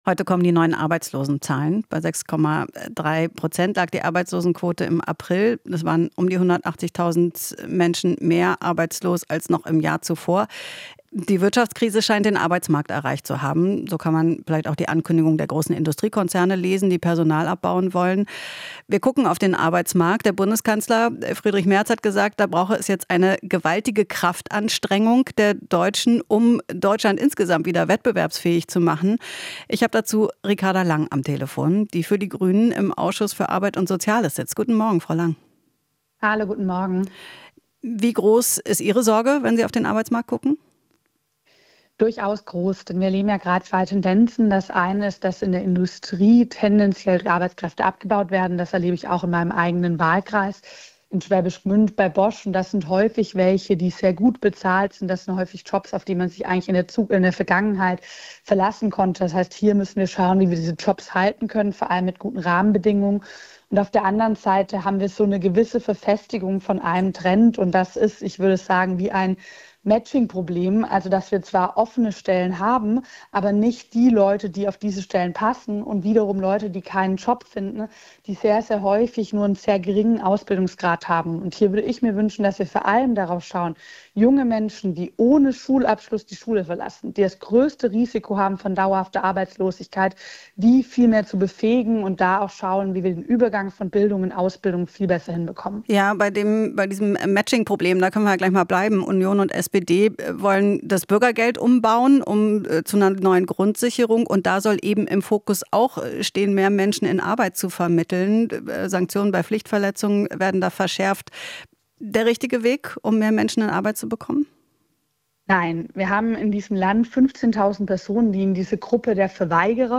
Interview - Lang (Grüne): Merz schiebt Verantwortung zu den Bürgern